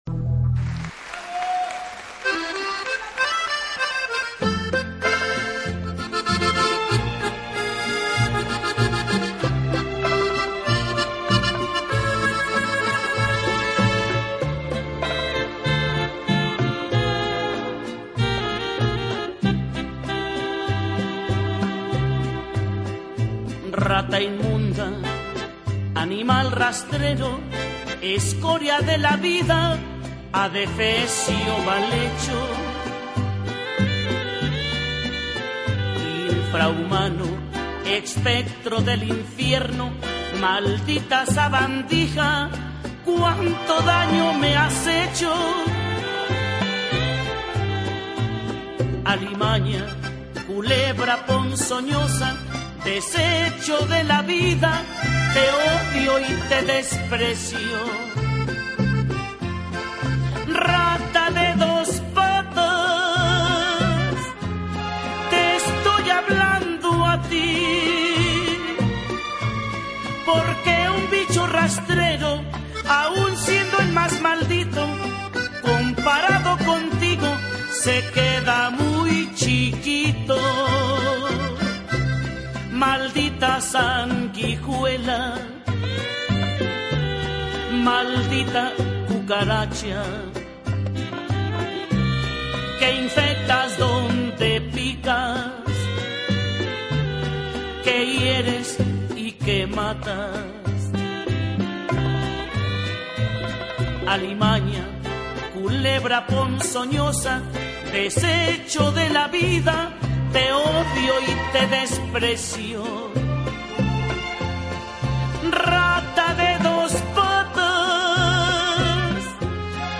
Carpeta: Lentos en español mp3
En Vivo